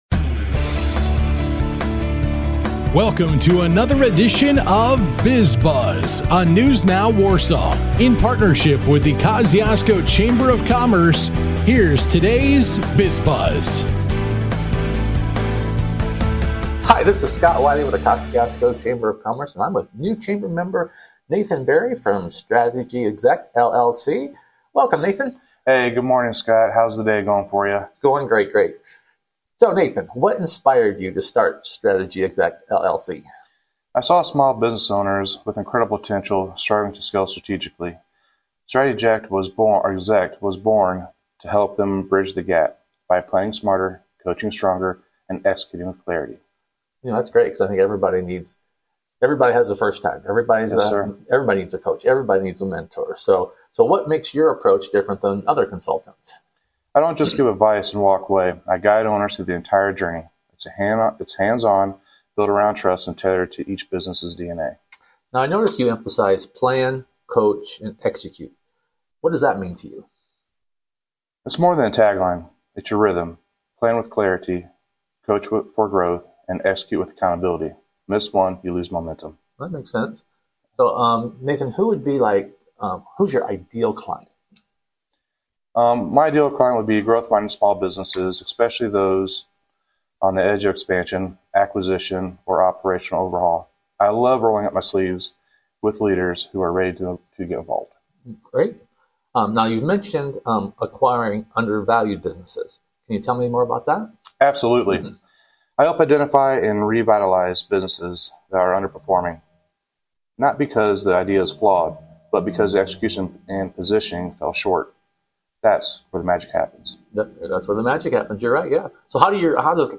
This week, a conversation with Strategy Exec LLC, Mason Health, and Columbis City Bridal.